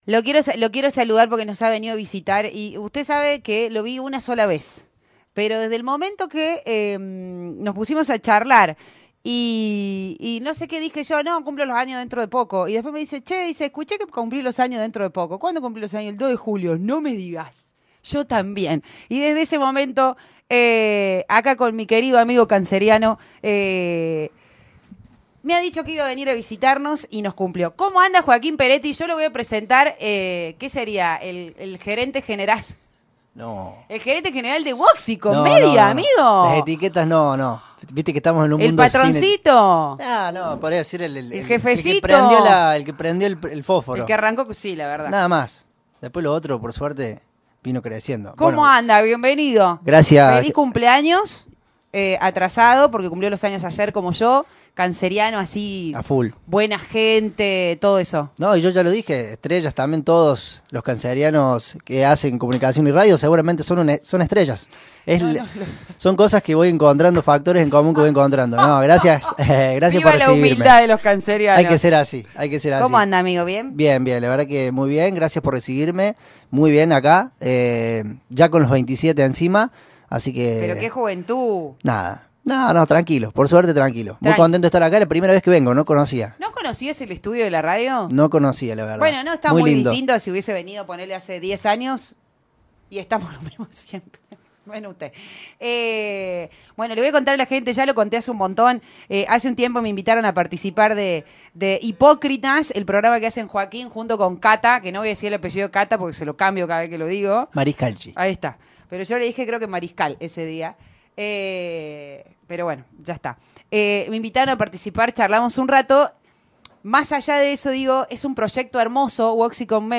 visitó nuestra radio y comentó sobre la propuesta comunicacional